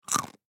Звуки фисташек
Здесь собраны уникальные записи: от мягкого шелеста скорлупы до насыщенного хруста при разламывании.
Хруст фисташки во рту